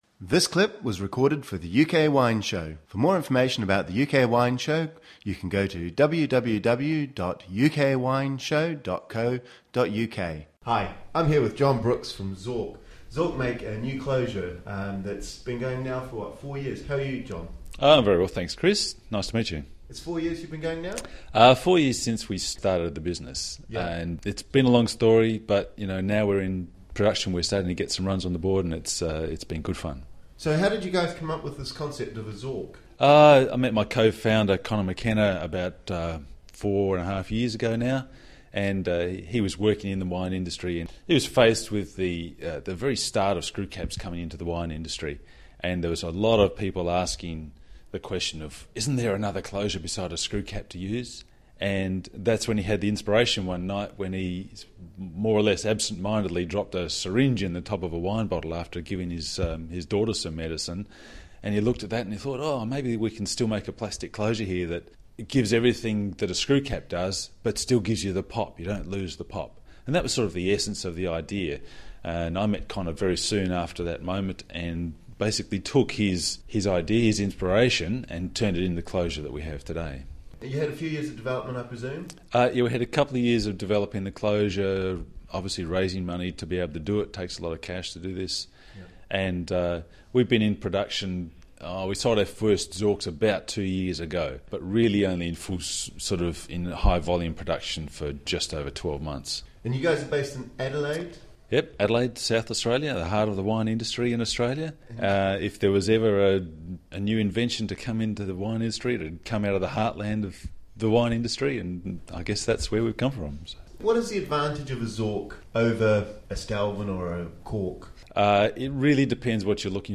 2006 » Listen to the interview play pause stop mute unmute max volume UK Wine Show 23 The Zork!